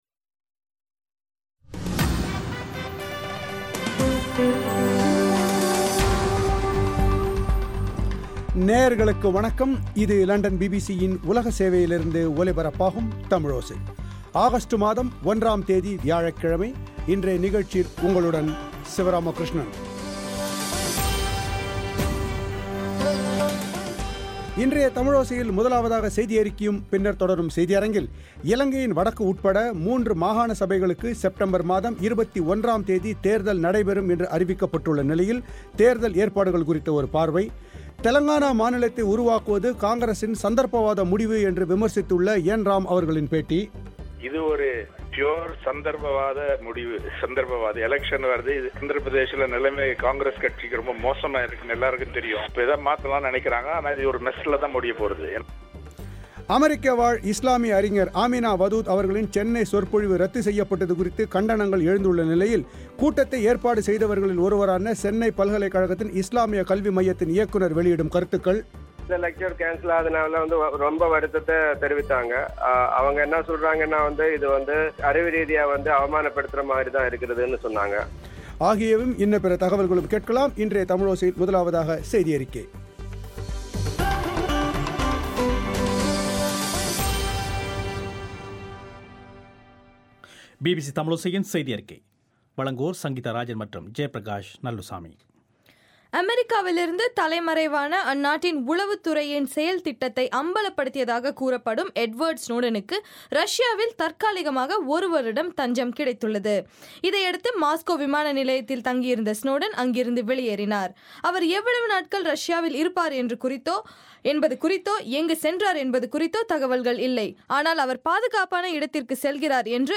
இன்றைய தமிழோசையில் இலங்கையின் வடக்கு உட்பட மூன்று மாகாண சபைகளுக்கு நடைபெறவுள்ள தேர்தலுக்கான ஏற்பாடுகள், தெலங்கா மாநிலத்தின் உருவாக்கம் குறித்து என். ராம் அவர்களின் பேட்டி.